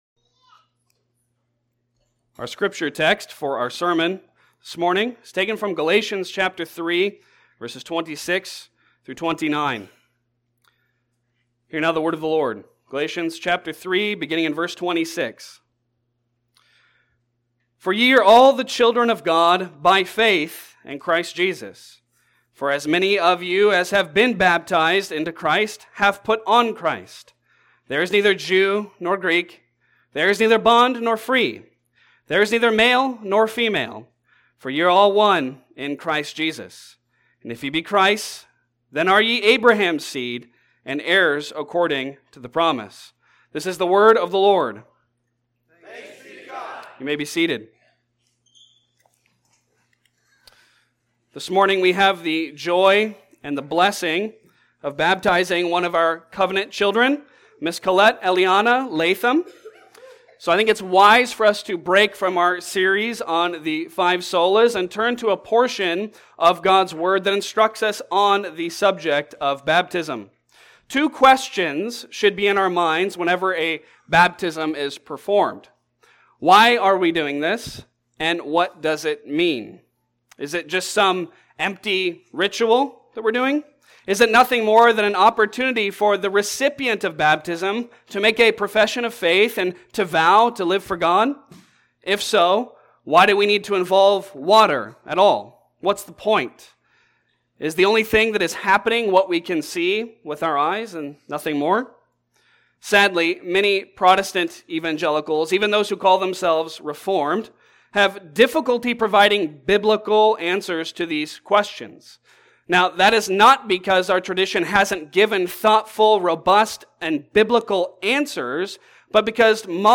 Passage: Galatians 3:26-29 Service Type: Sunday Sermon Download Files Bulletin « Soli Deo Gloria